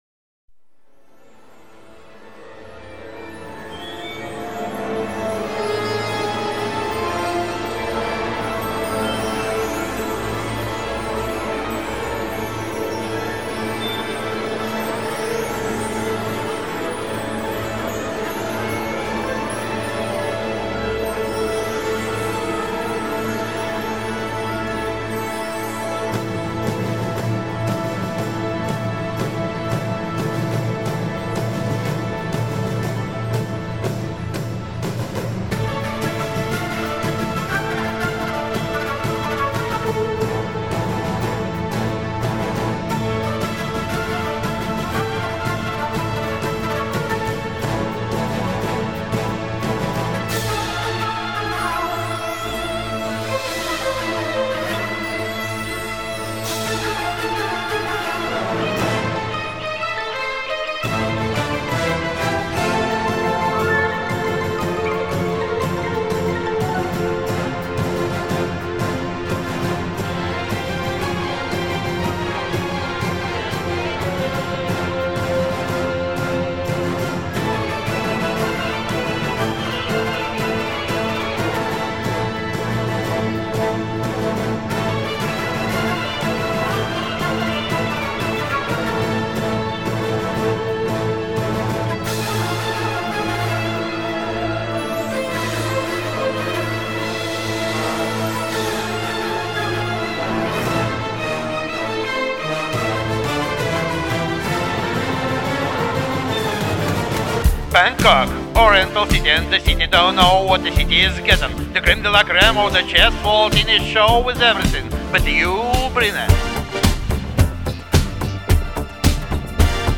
И резко так все, на грани крика....